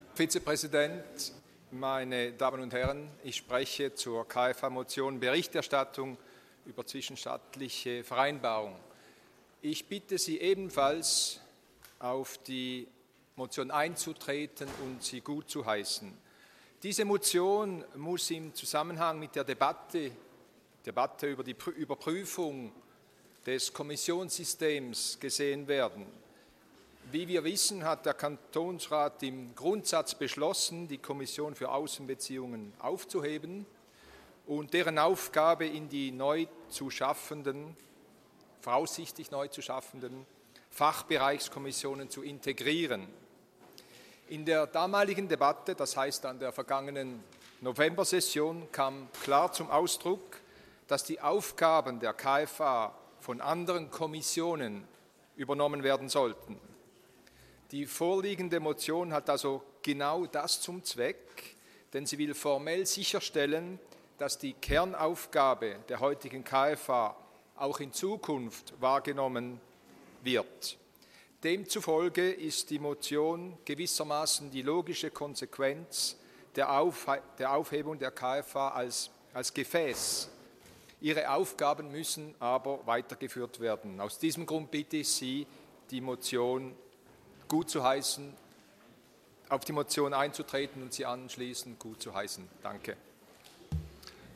2.6.2015Wortmeldung
Session des Kantonsrates vom 1. bis 3. Juni 2015